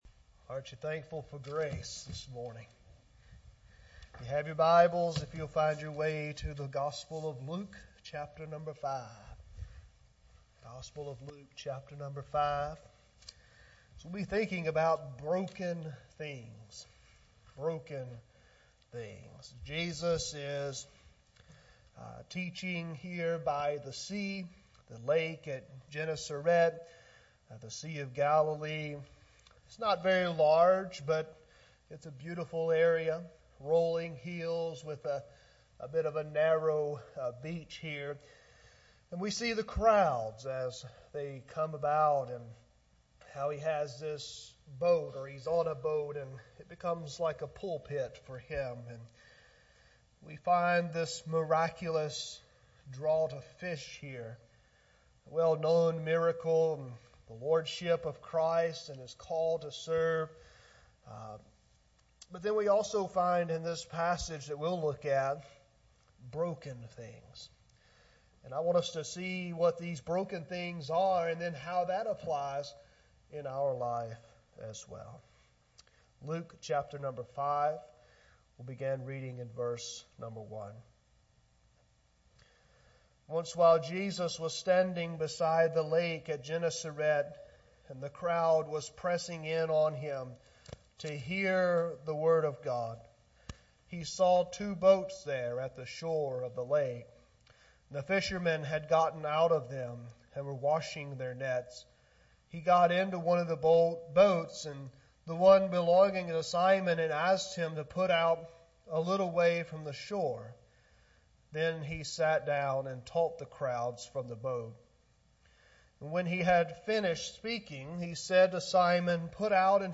Sermons | West Acres Baptist Church
Guest Speaker